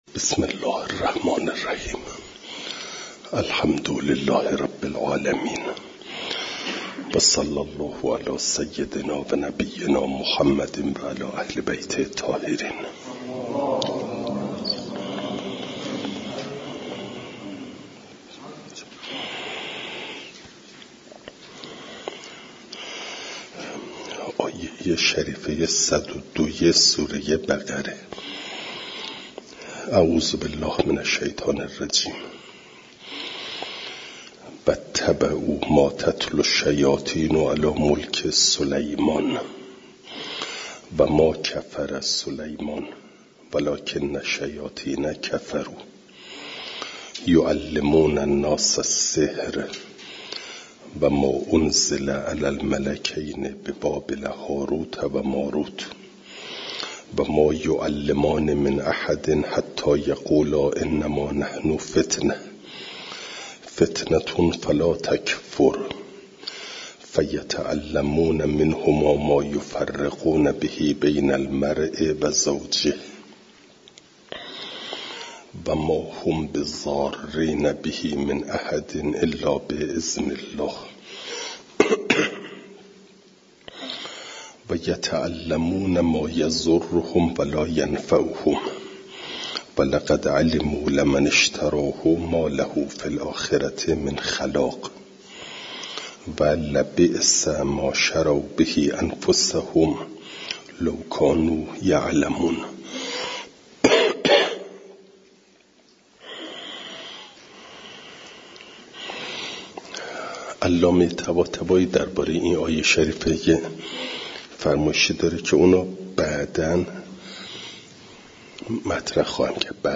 درس تفسیر مجمع البیان